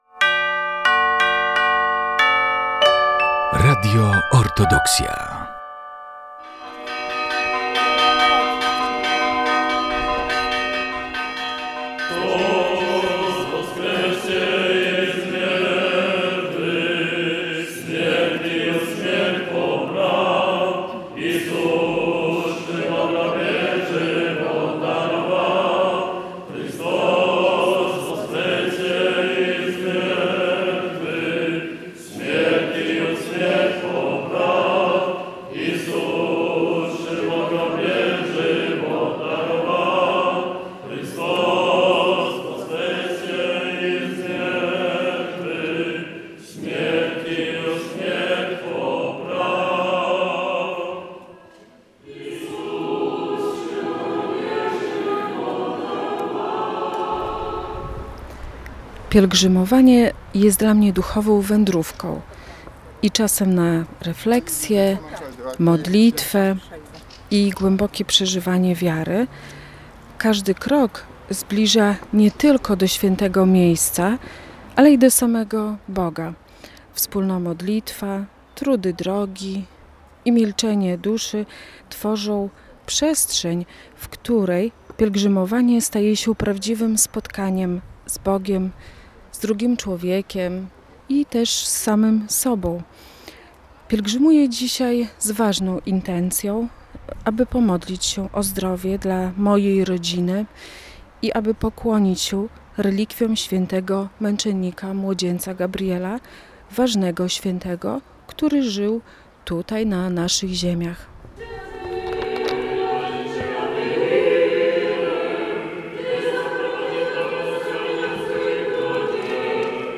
Reportaż Piesza Pielgrzymka Białystok-Zwierki 2025